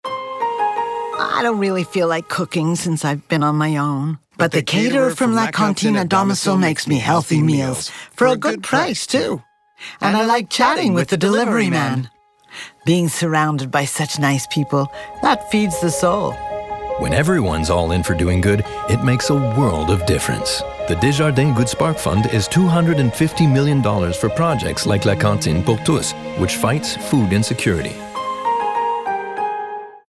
Commercial (Desjardins) - EN